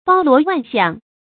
注音：ㄅㄠ ㄌㄨㄛˊ ㄨㄢˋ ㄒㄧㄤˋ
包羅萬象的讀法